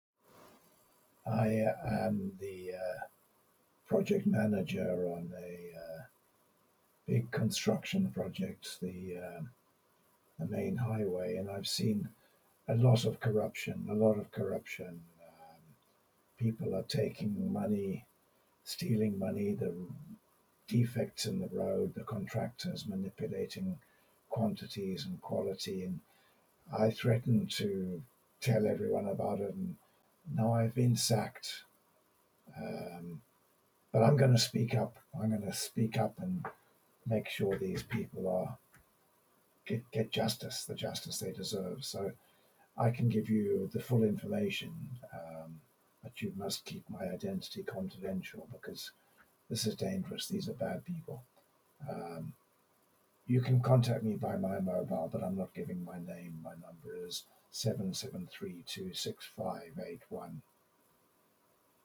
The Whistleblower Interview
AUDIO RECORDING OF WHISTLEBLOWER REPORT BY TELEPHONE